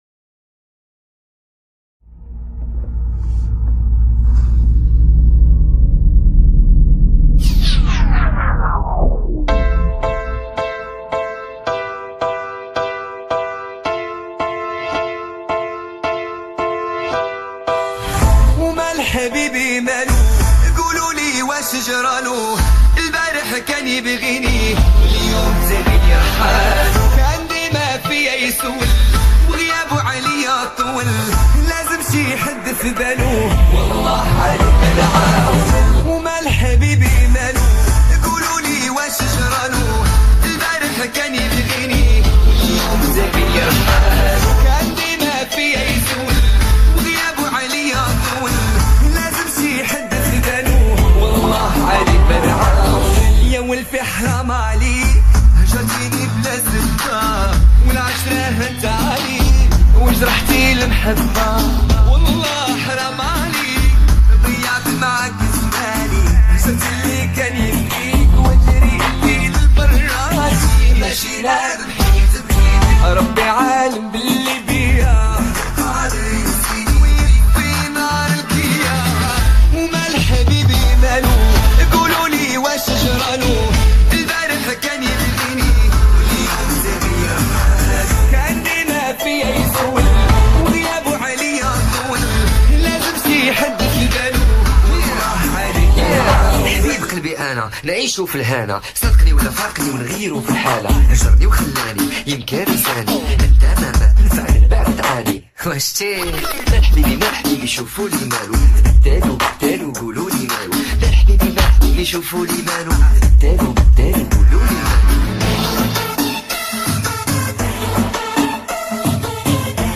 ورژن 8 بعدی